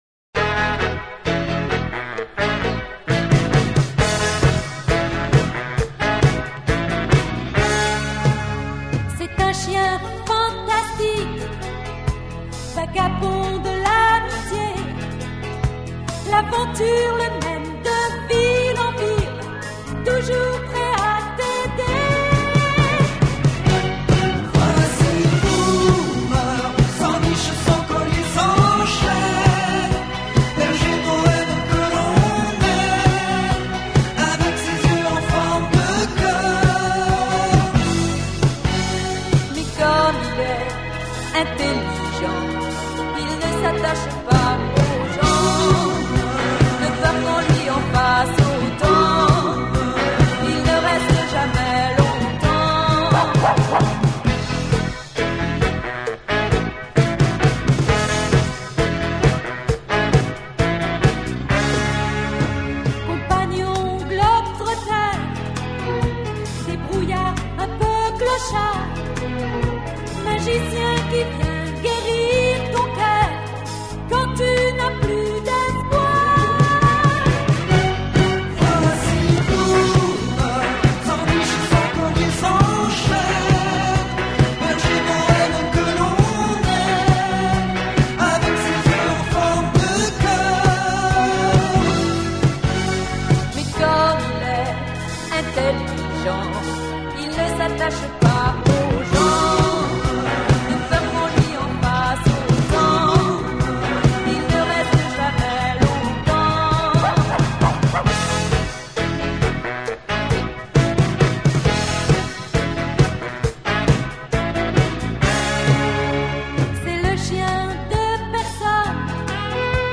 Weekly theme in French           2:30